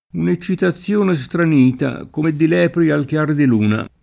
un e©©itaZZL1ne Stran&ta k1me ddi l$pri al kL#r di l2na] (Calvino) — sim. il pers. m. Chiaro (oggi solo nel femm. Chiara), i cogn. Chiara, ‑ri, ‑ro, Dalla Chiara, De Chiara, Del Chiaro, Della Chiara, e il top. Chiari (Lomb.) — cfr. Anna Chiara; Clara; Di Chiara; Maria Chiara